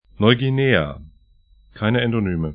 Neuguinea nɔygi'ne:a